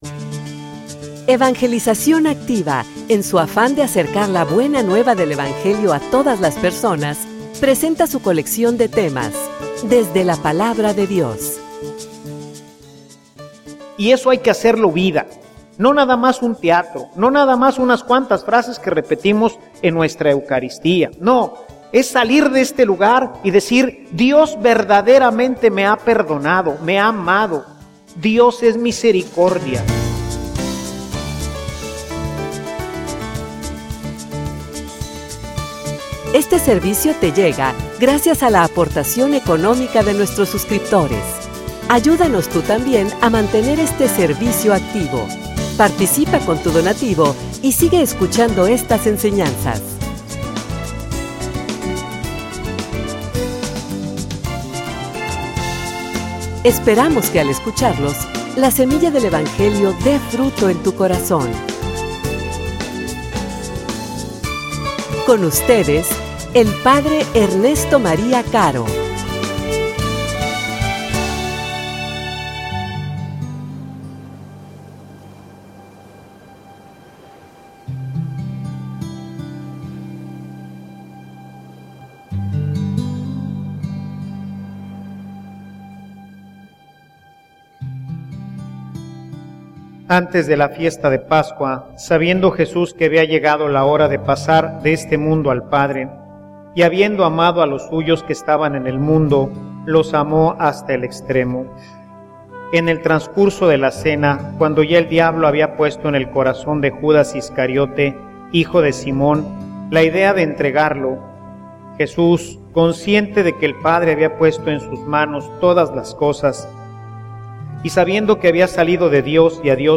homilia_Memorializar_es_repetir.mp3